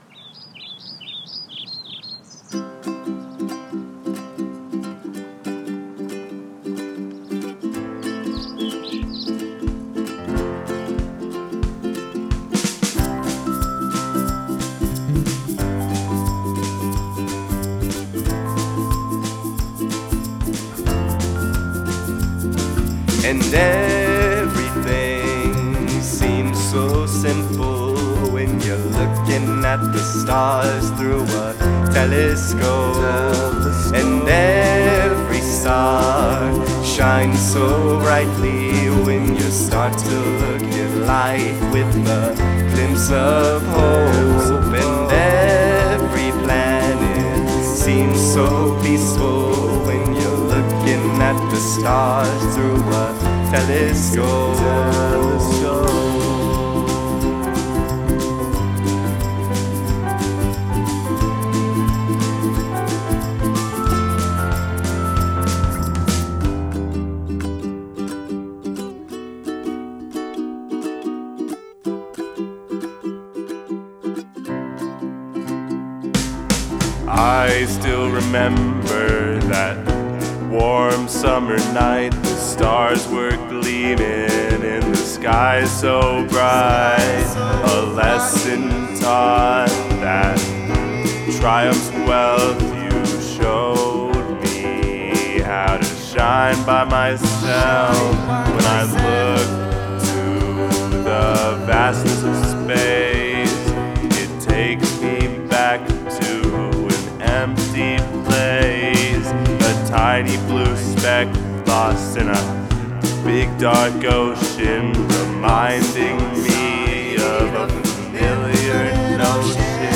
wholesome song
We had three vocalists, one of them also playing bass and another playing Ukulele, and I played piano and vibraphone!